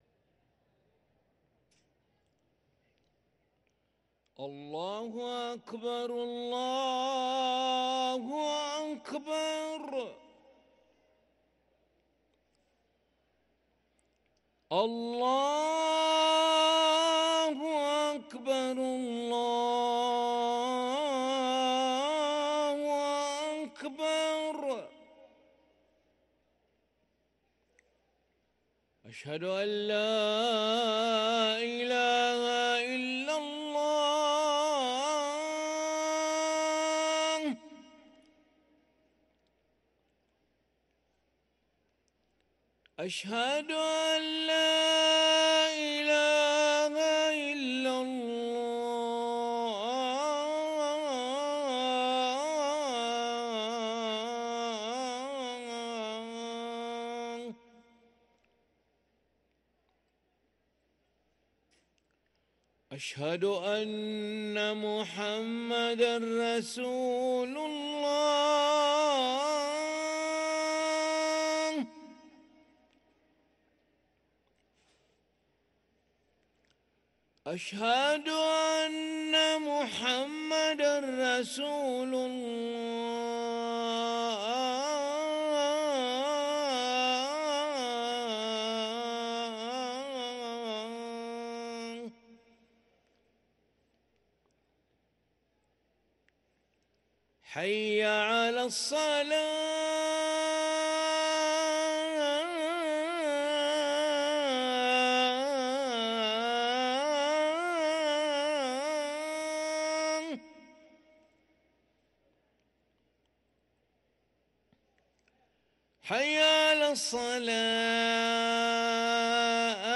أذان العشاء للمؤذن علي ملا الأحد 7 رجب 1444هـ > ١٤٤٤ 🕋 > ركن الأذان 🕋 > المزيد - تلاوات الحرمين